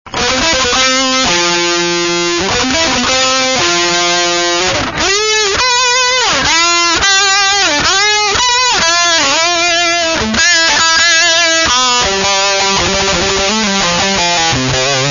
lead guitar.